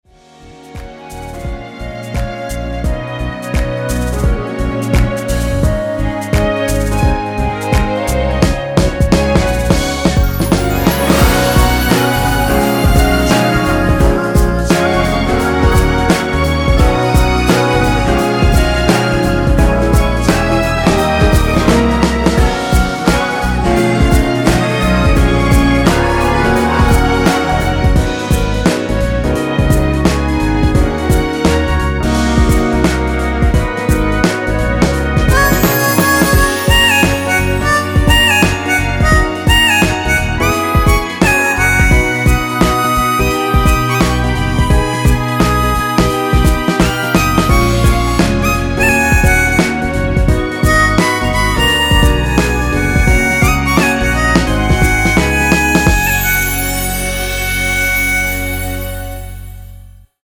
엔딩이 페이드 아웃이라서 노래하기 편하게 엔딩을 만들어 놓았으니 미리듣기 확인하여주세요!
원키에서(-2)내린 멜로디와 코러스 포함된 MR입니다.
Gb
앞부분30초, 뒷부분30초씩 편집해서 올려 드리고 있습니다.